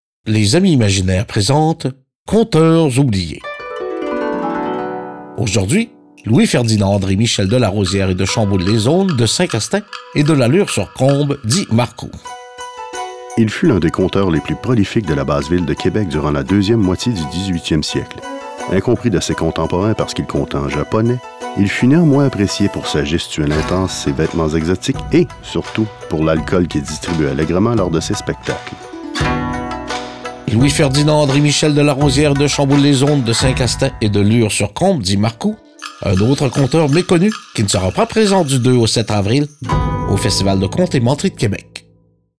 Du 18 mars au 3 avril, quatre capsules humoristiques sur le conte et la menterie sont diffusées sur les ondes de CKRL 89,1.